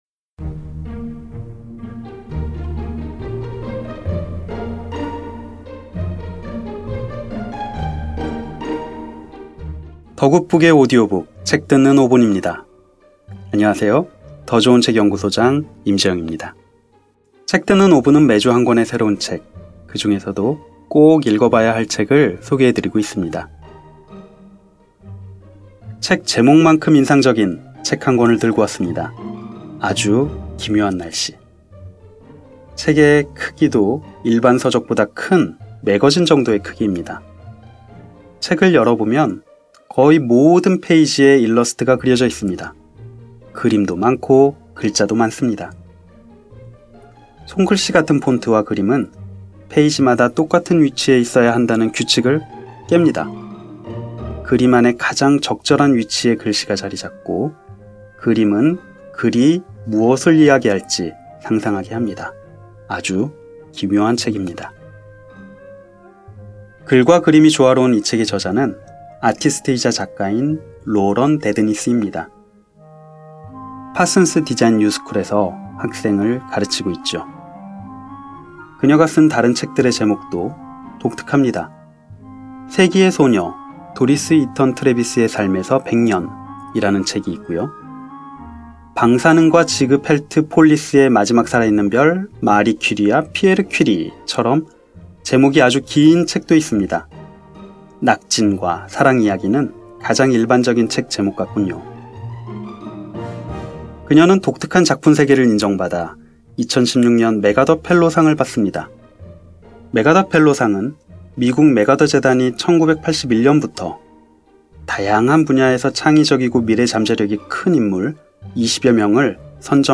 매거진 책 듣는 5분 ㅣ 오디오북